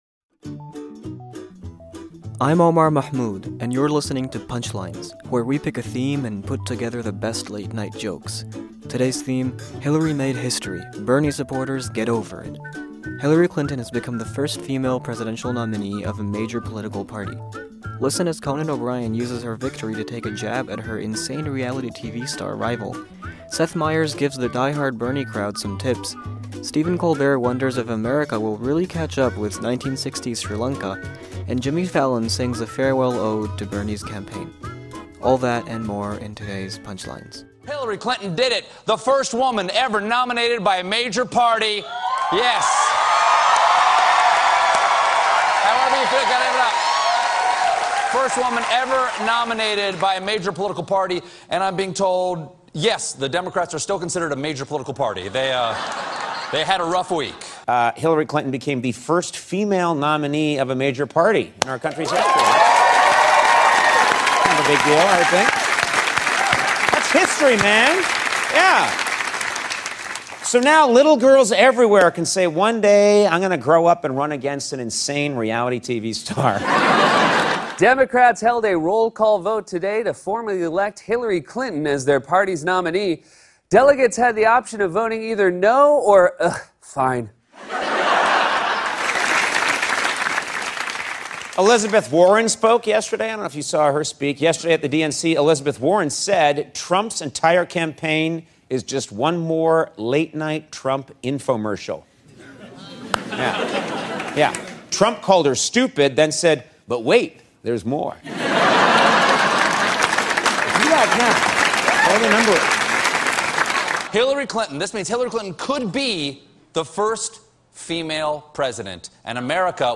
The late-night comics give us their take on the latest from the DNC.